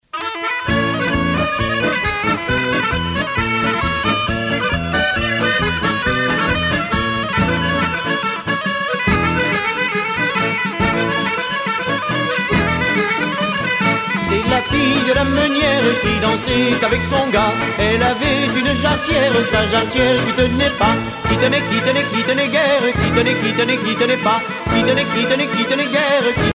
Chants brefs - A danser
danse : polka piquée